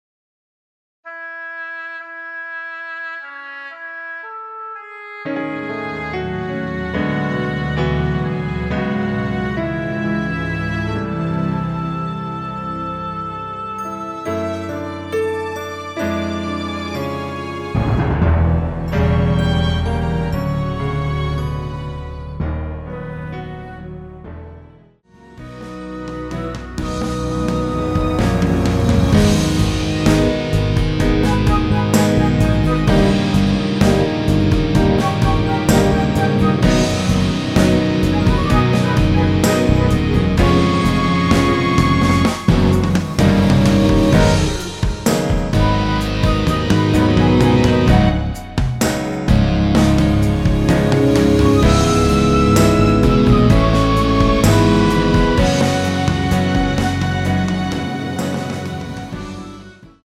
축가로 좋은 곡
노래방에서 음정올림 내림 누른 숫자와 같습니다.
앞부분30초, 뒷부분30초씩 편집해서 올려 드리고 있습니다.